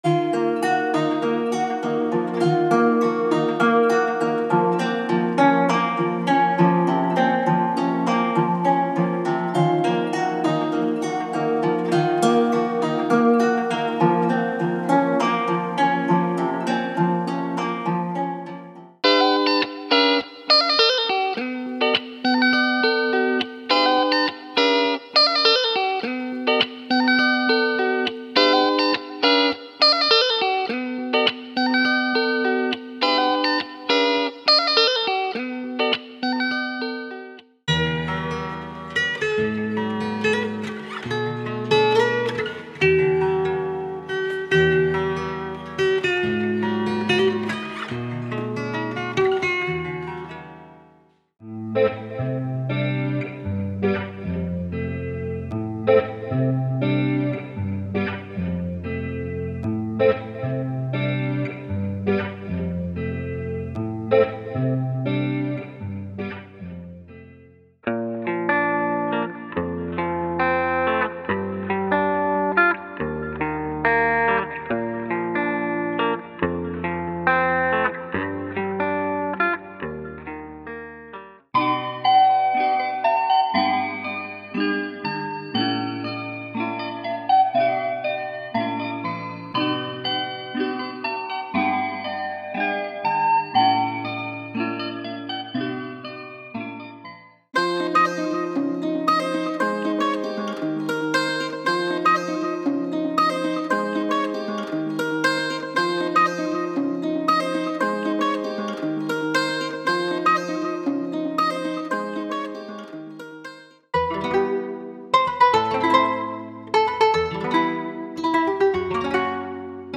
富有表现力的吉他音色层层叠加，为音乐增添色彩和活力。
所有音色均使用专业外置设备进行处理和编辑，以确保其拥有纯正的模拟音色温暖感。